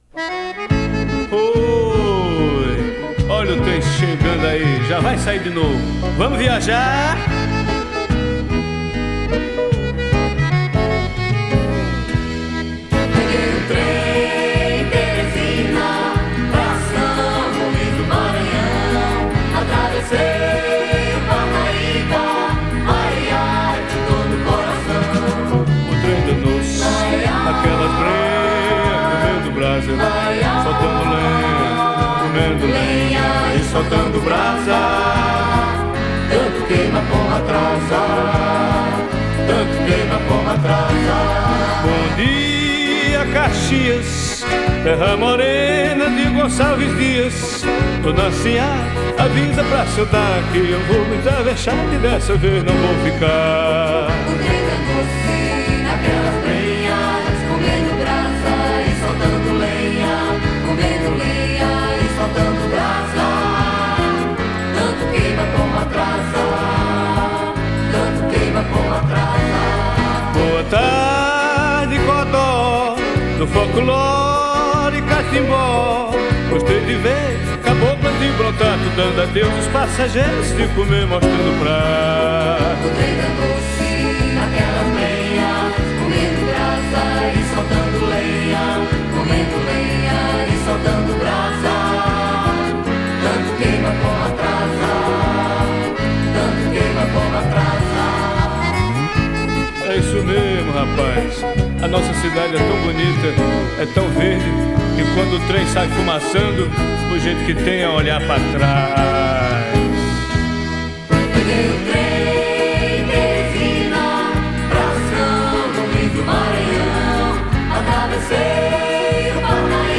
Vocal, Violao Acústico 6
Guitarra
Acoordeon
Baixo Elétrico 6
Bateria
Percussão